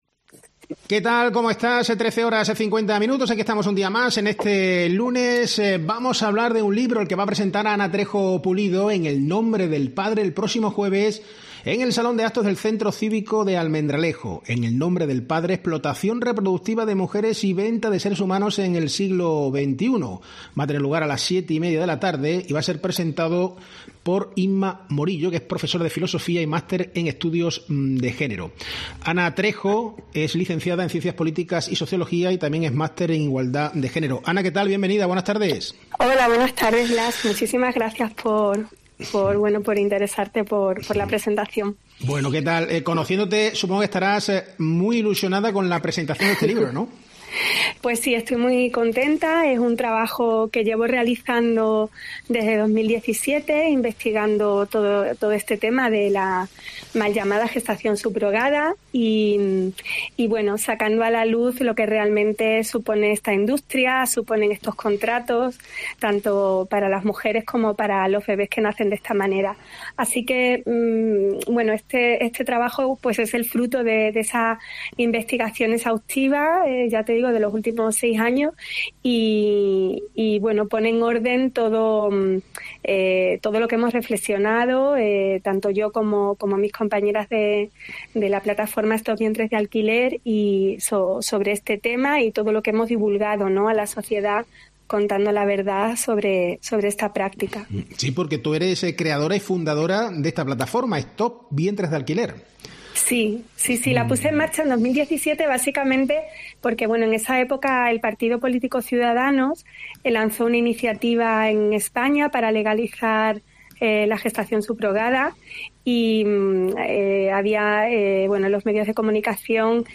En COPE hemos hablado con ella.